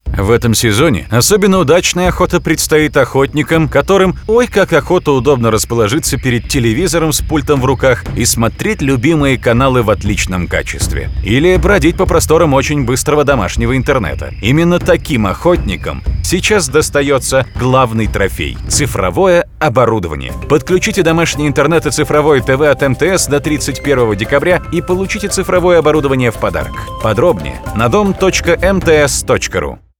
федеральный ролик для компании МТС